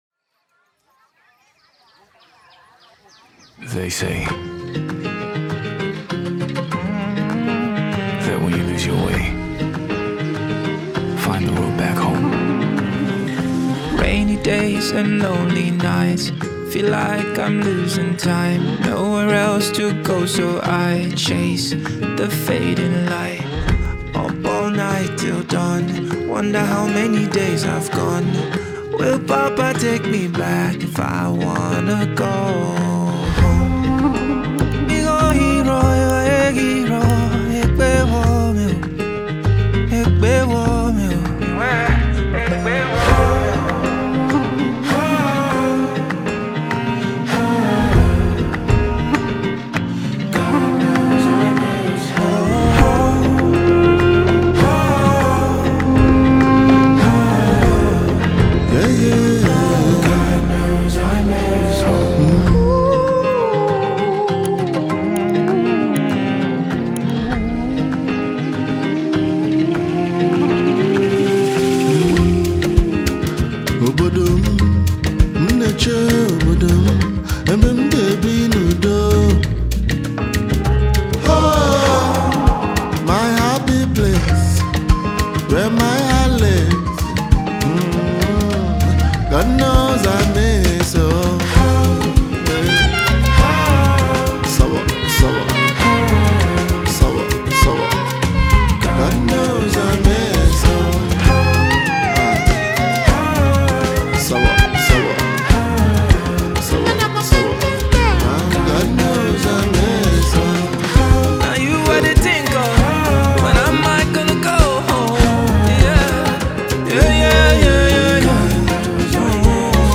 Renowned Nigerian singer and performer
with vocal assistance